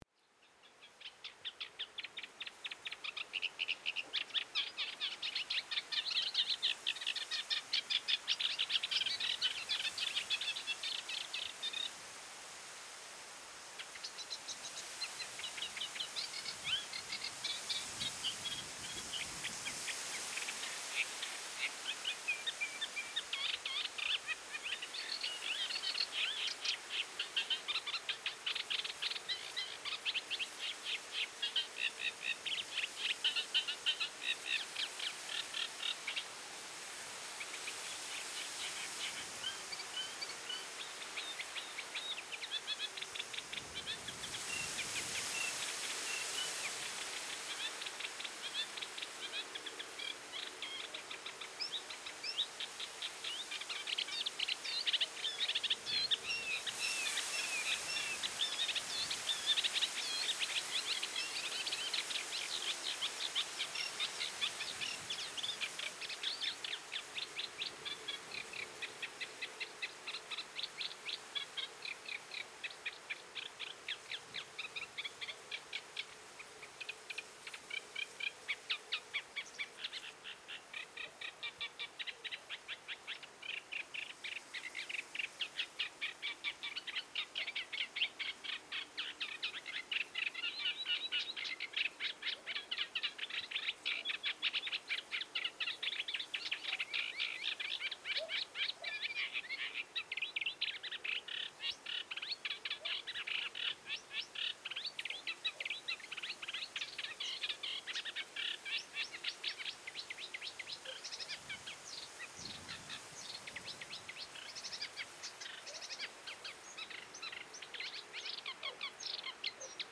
Rosery bird
Surrounded by grasses almost taller than me, I am immersed in the intoxicating song of birds. They are undoubtedly reed warblers.